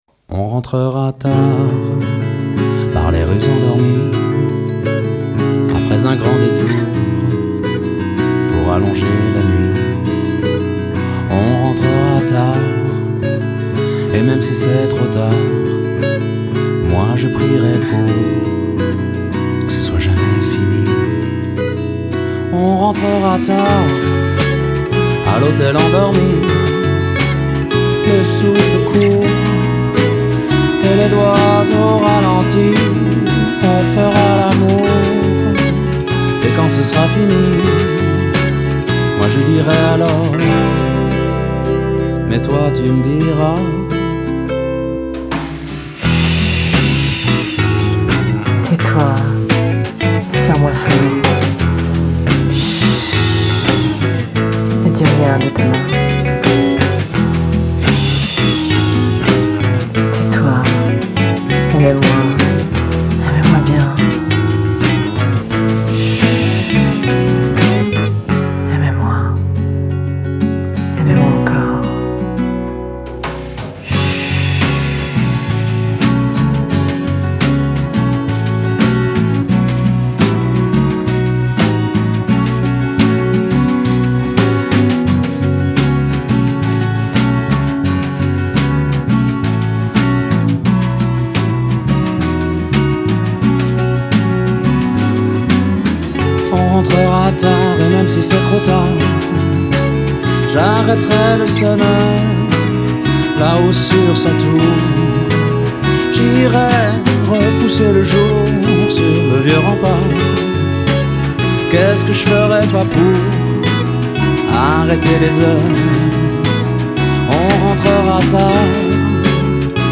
enregistré en 2006 au studio du moulin
guitare, chant
basse
batterie